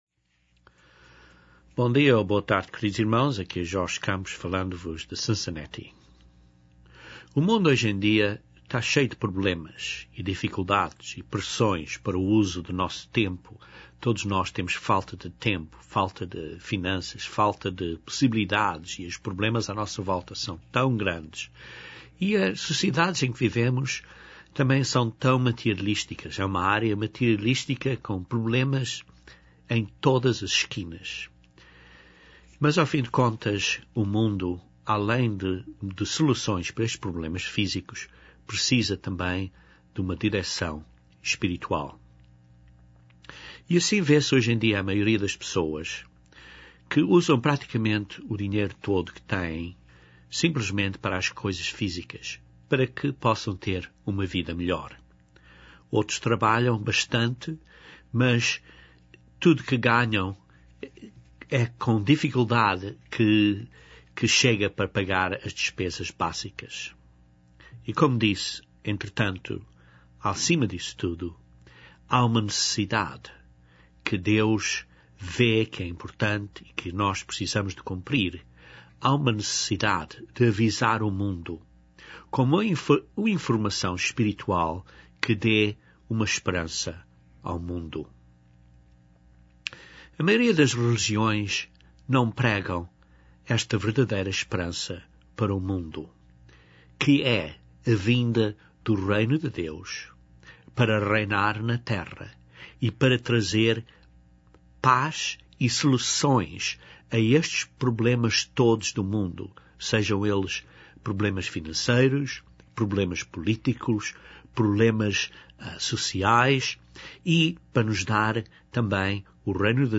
Este sermão descreve este tema importante.